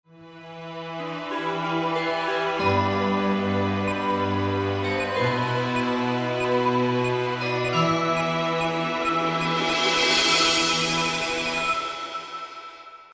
Here are the two musical samples: